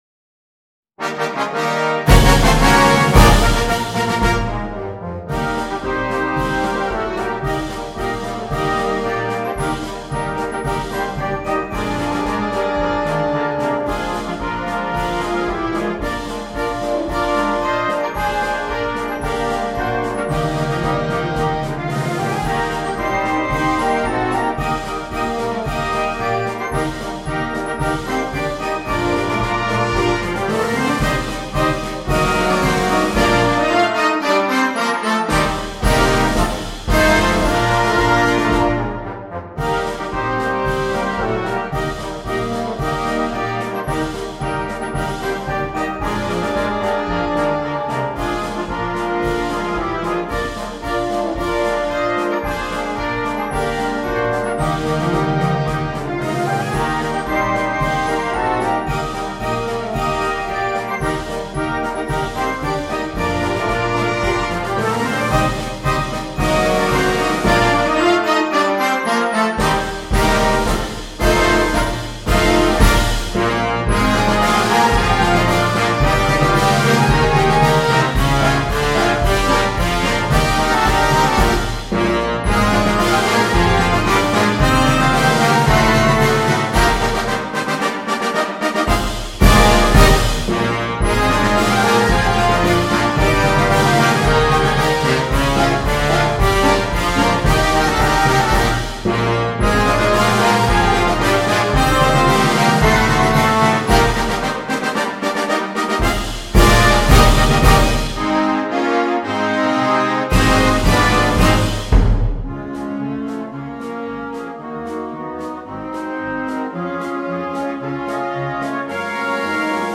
Besetzung: Concert Band